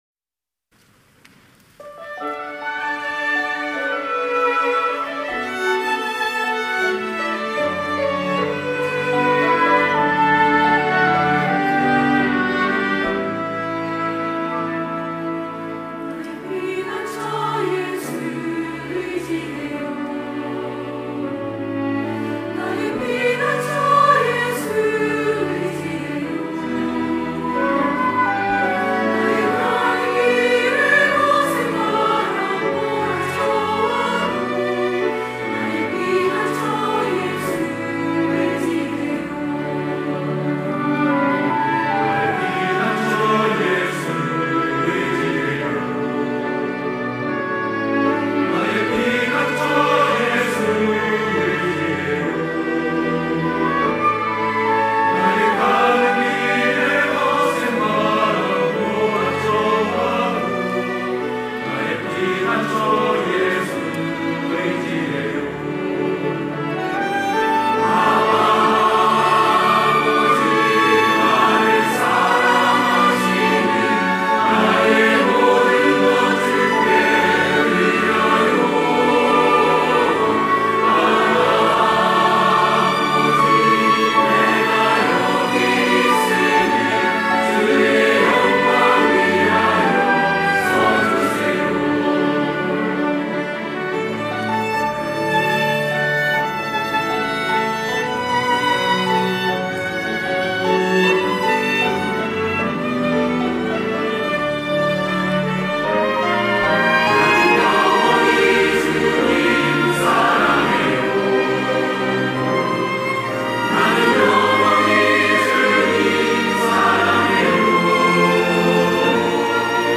호산나(주일3부) - 나의 피난처 예수
찬양대 호산나